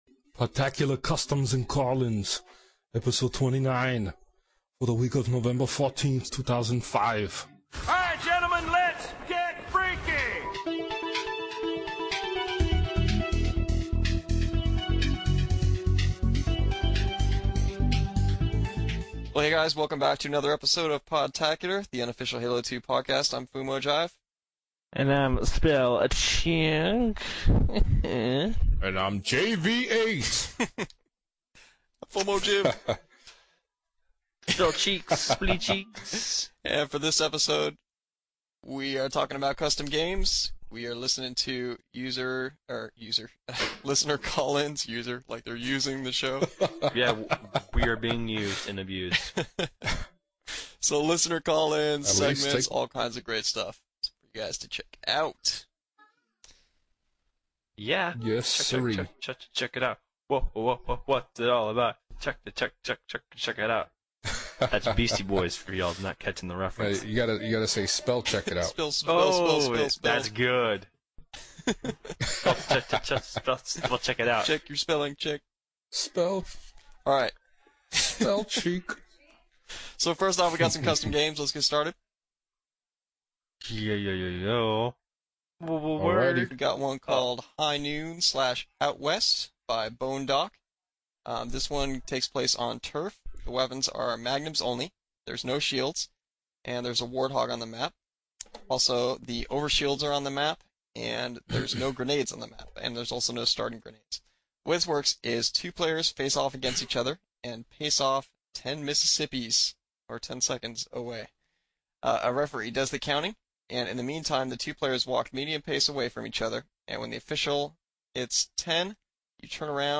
This show we talk about some cool Custom Games and play your listener voice mails and segments.